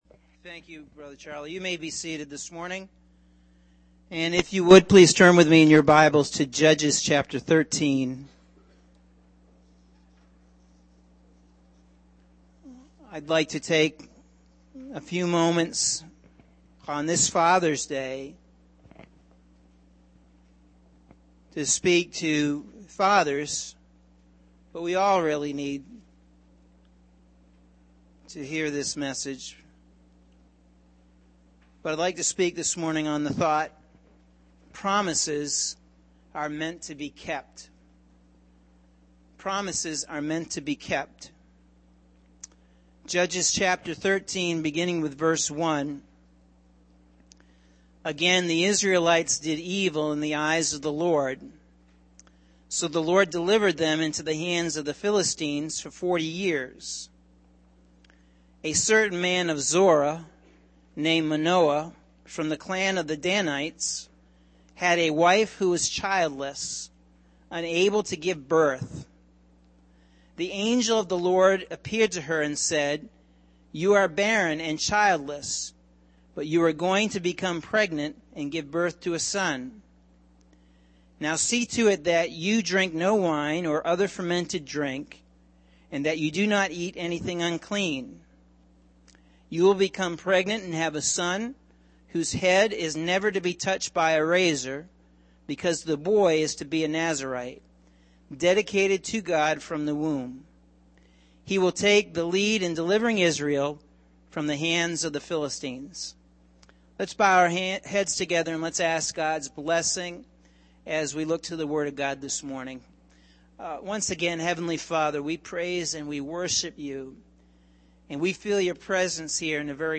Sunday June 19th – AM Sermon – Norwich Assembly of God